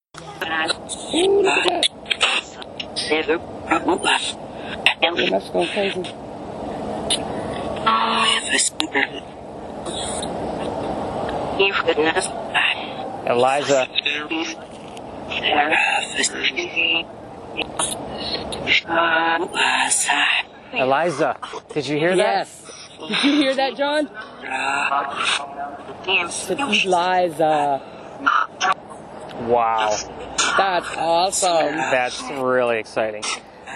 In other words, it takes radio frequencies, scans them, but plays back the words from DJs, songs and other programs in reverse. First off, it sounds amazingly creepy and cool.
Here’s an example of the reverse spirit box activity I captured one night with two guests. I asked the spirit to tell us her name.
eliza-reverse-spirit-box.mp3